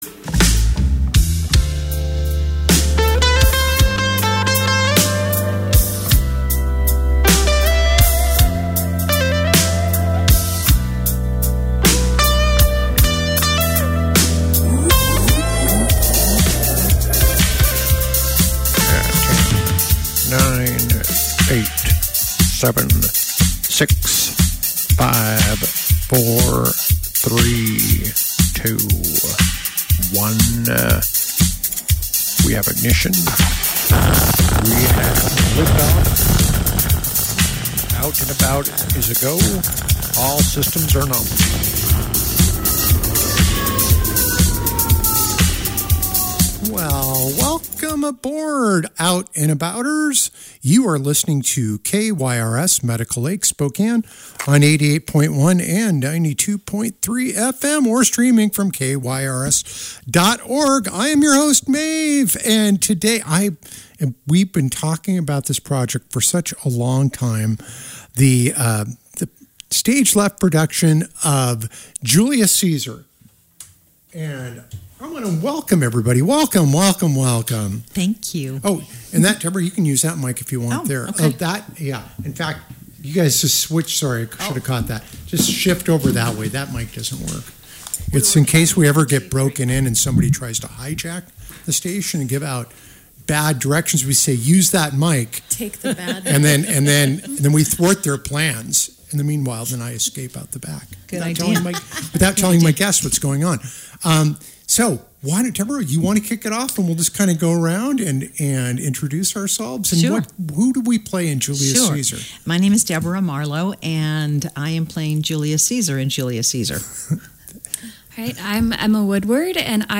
Tune into Out and About every Tuesday at 4 pm on KYRS at 88.1 and 92.3 fm.